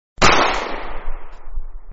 gunshoot.wav